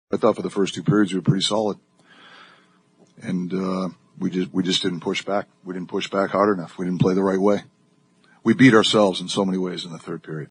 Coach Mike Sullivan says the Islanders punched back at the Penguins in the third period, and his team did not respond.